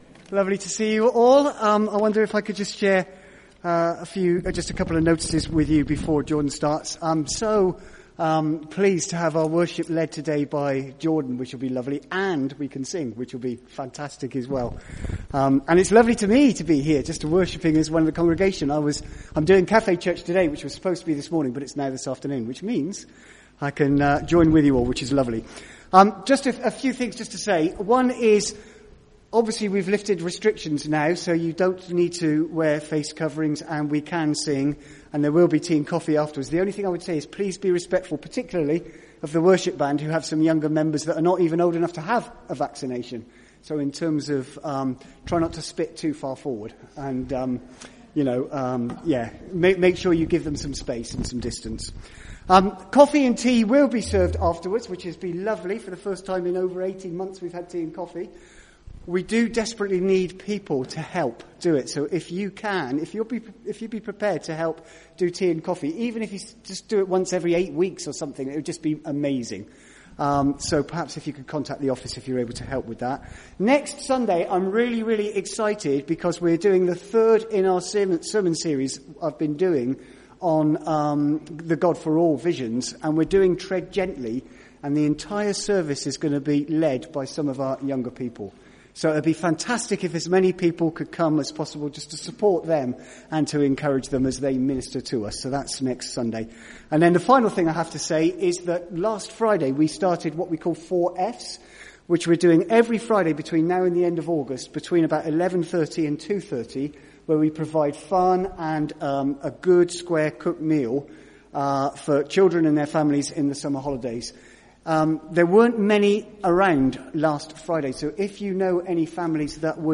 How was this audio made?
From Service: "10.30am Service"